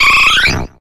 Audio / SE / Cries / EEVEE.ogg